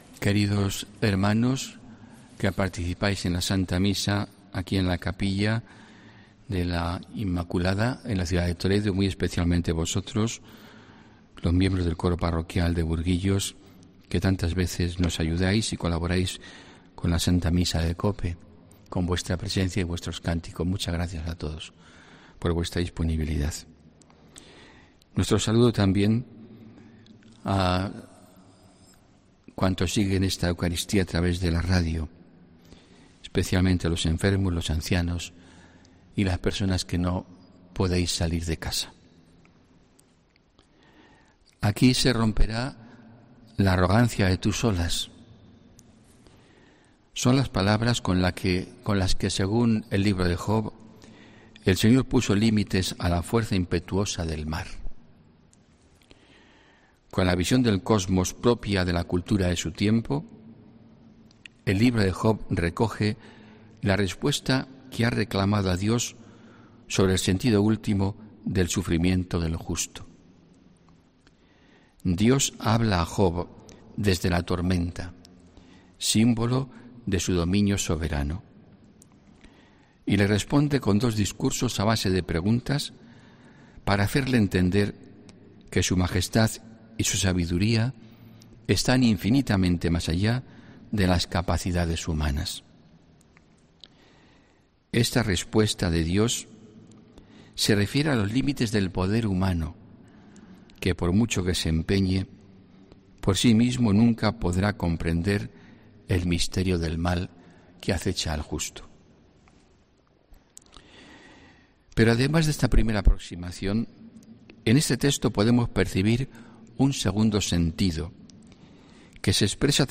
HOMILÍA 20 JUNIO 2021